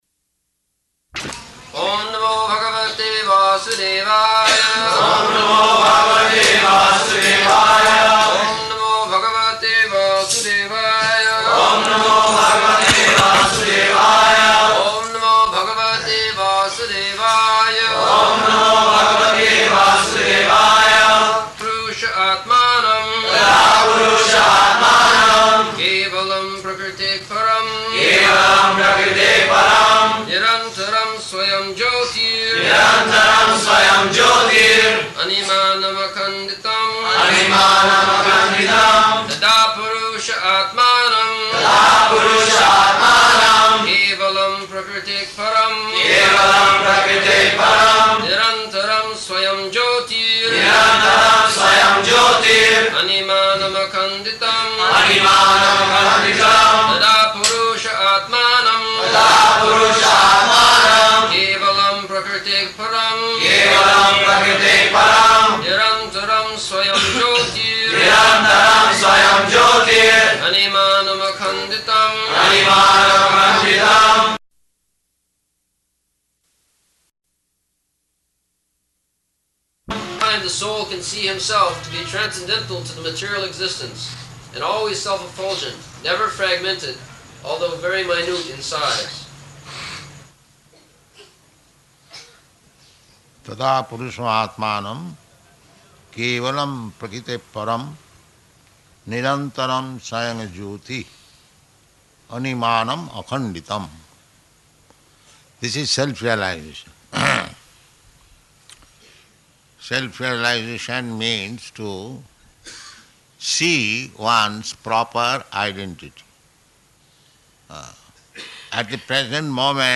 -- Type: Srimad-Bhagavatam Dated: November 17th 1974 Location: Bombay Audio file
[devotees repeat] [leads chanting of verse, etc.]